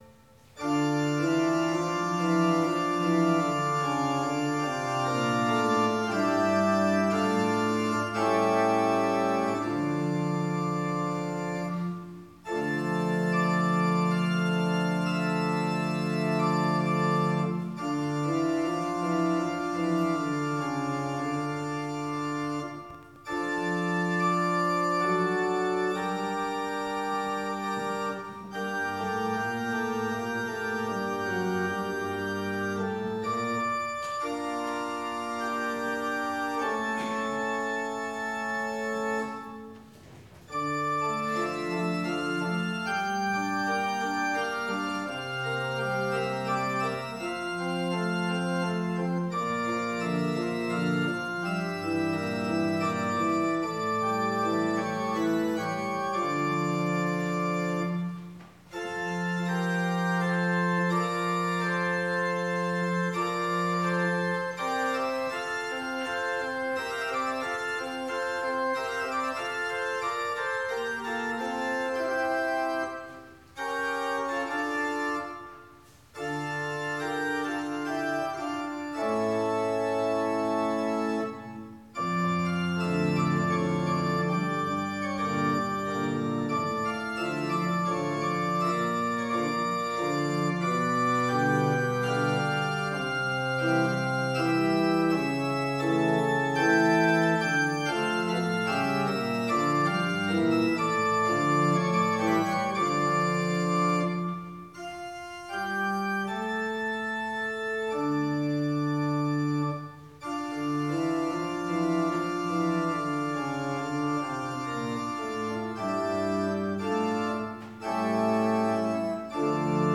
Gottesdienst am 15. März (Herzhausen)